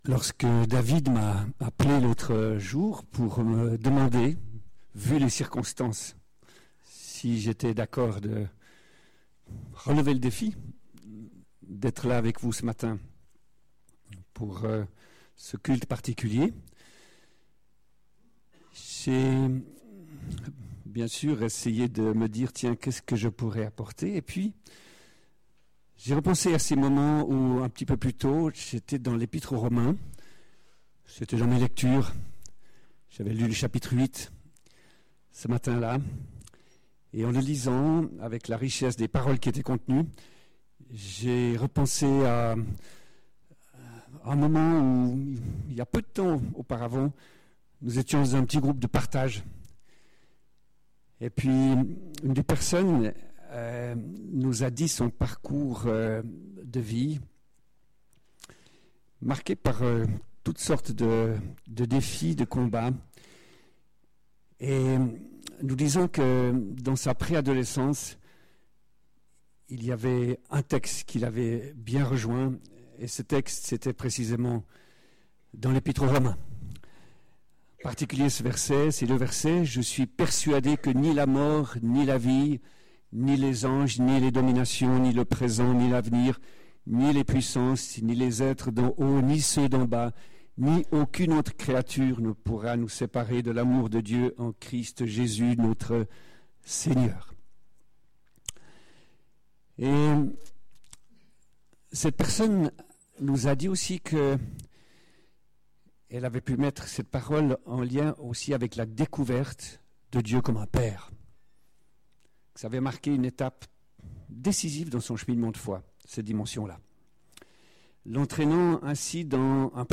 Culte du 1er mai 2016